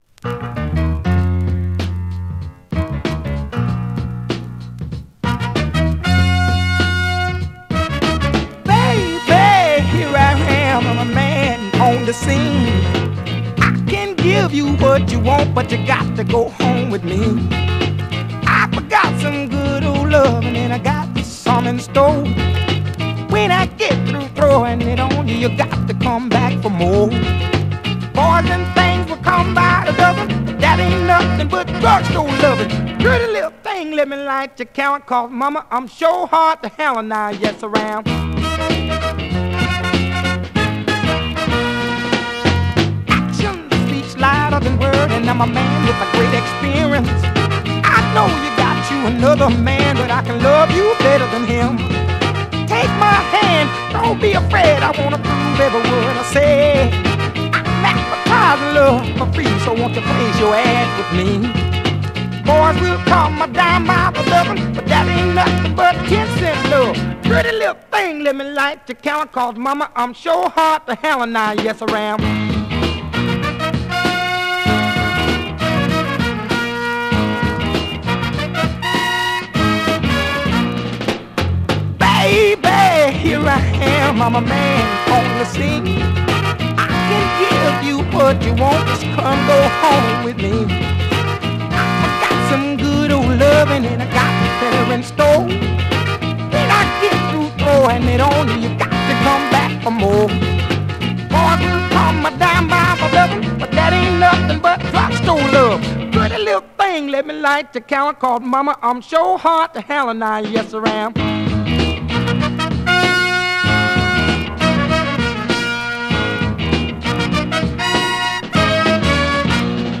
Classic Soul Funk Mod